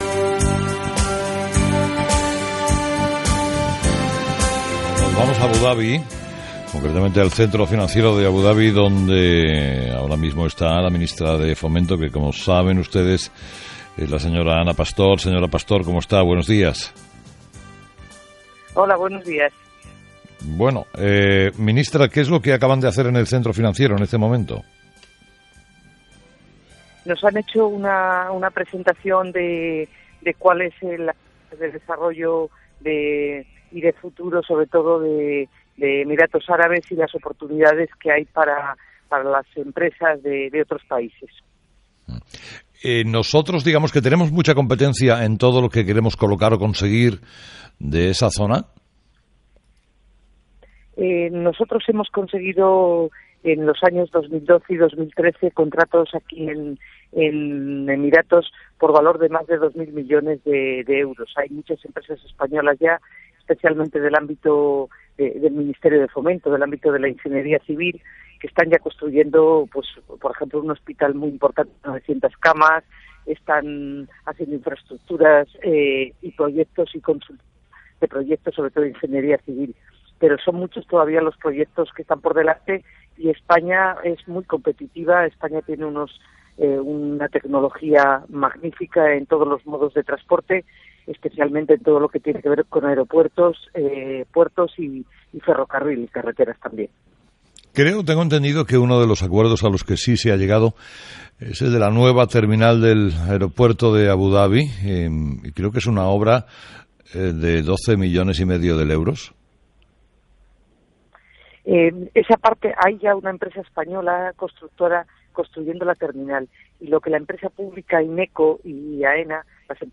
Entrevista a Ana Pastor en Abudabi